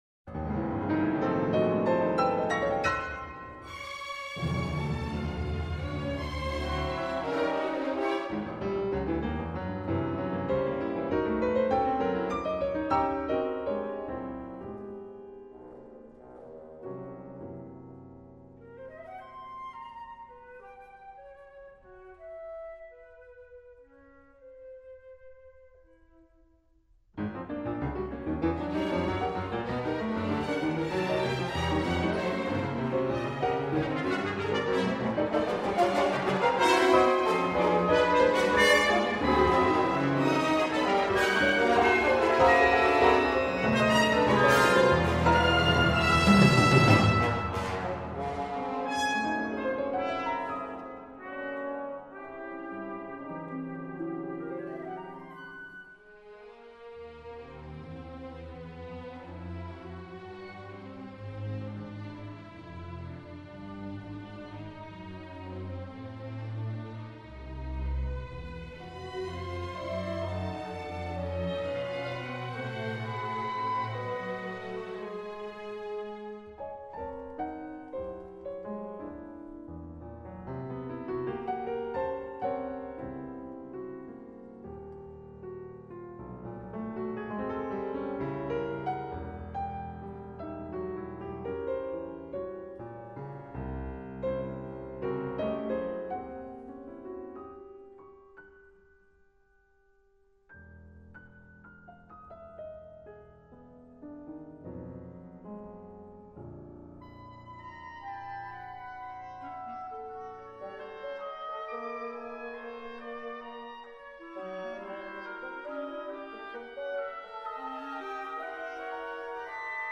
for piano and orchestra